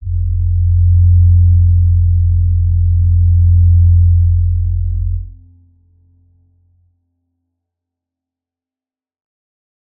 G_Crystal-F2-f.wav